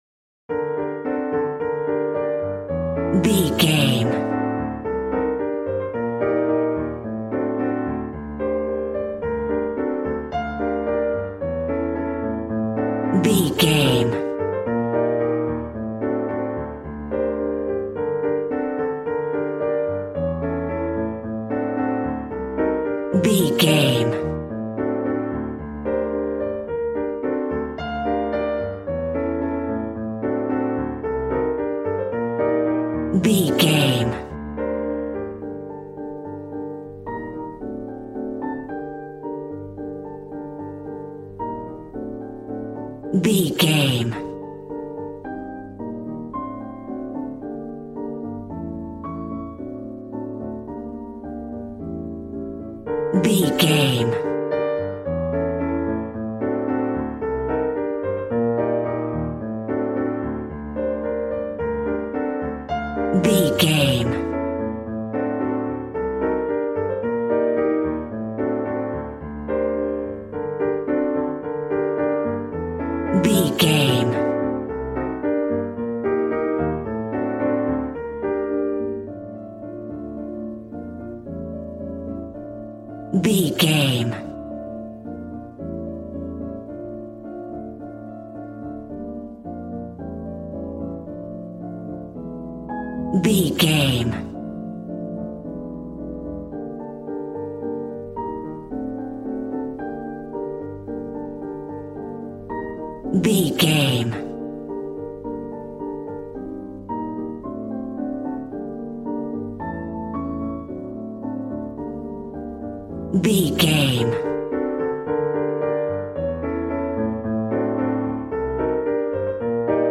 Aeolian/Minor
passionate
acoustic guitar